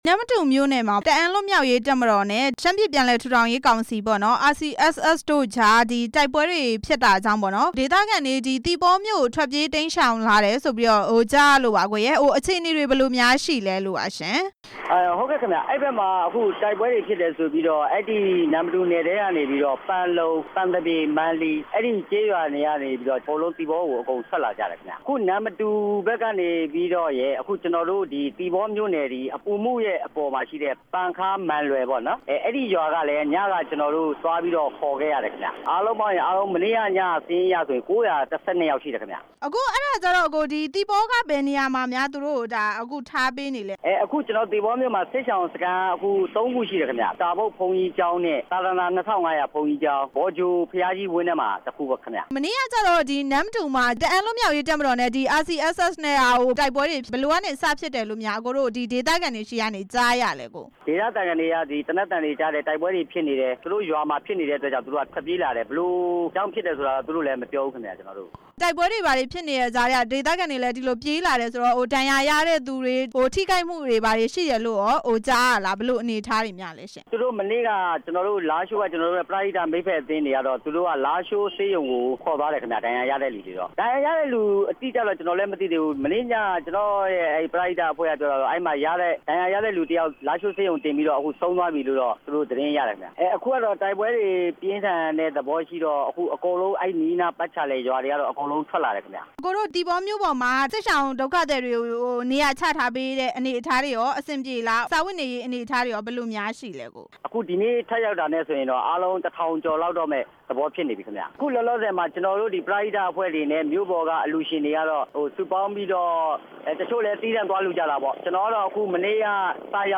သီပေါမြို့ကို ထွက်ပြေးလာတဲ့ စစ်ပြေးဒုက္ခသည်တွေ အခြေအနေ မေးမြန်းချက်